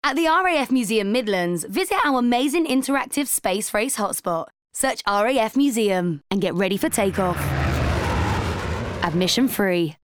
The sonic ident reflected the history and longevity of the RAF – by gradually morphing the sound of a spitfire-esque plane into that of a modern fighter jet.